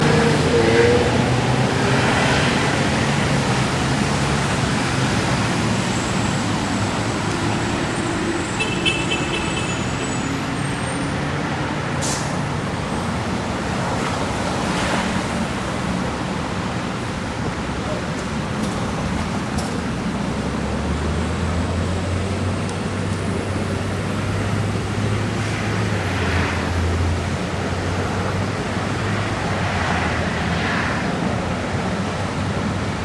Trafic
Corner of California Ave and Hempstead Turnpike.
Sounds heard: Squeaking breaks. Wheels on road. Car horn.